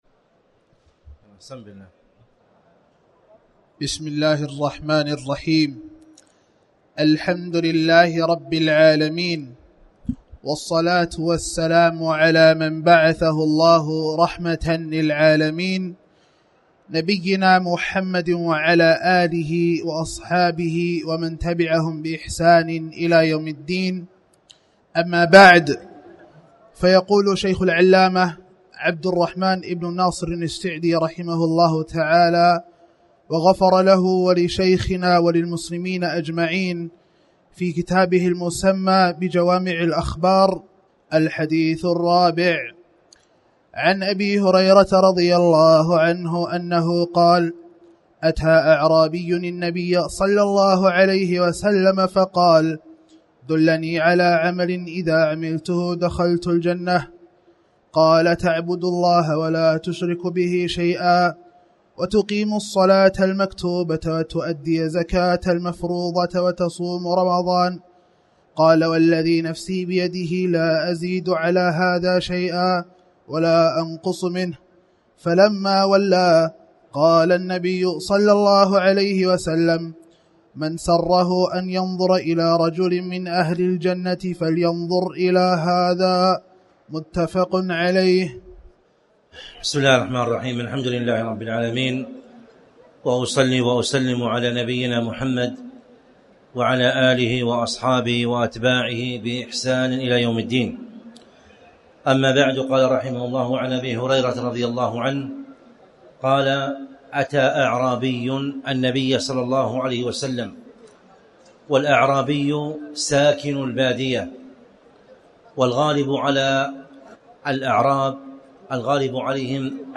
تاريخ النشر ٢١ رمضان ١٤٣٩ هـ المكان: المسجد الحرام الشيخ